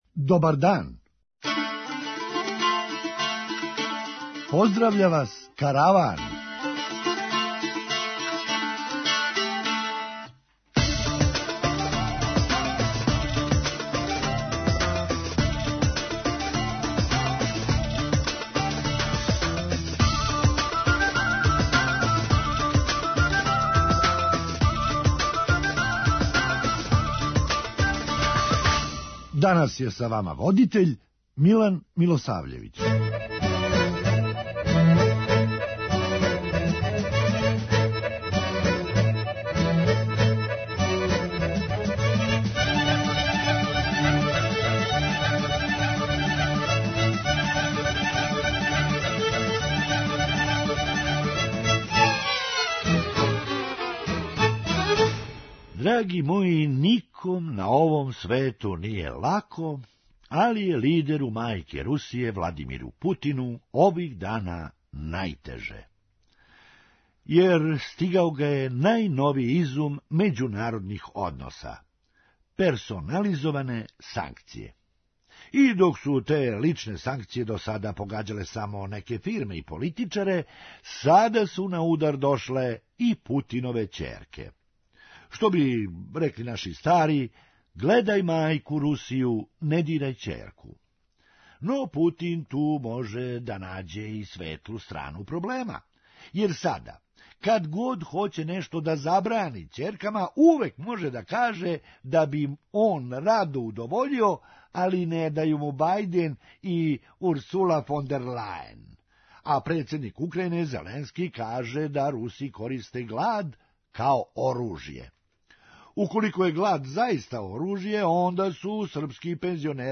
Хумористичка емисија
Можда буде захтевао да му се од следеће недеље замене и слова у његовом имену и презимену па да га у Црној Гори зову М-илун! преузми : 9.12 MB Караван Autor: Забавна редакција Радио Бeограда 1 Караван се креће ка својој дестинацији већ више од 50 година, увек добро натоварен актуелним хумором и изворним народним песмама.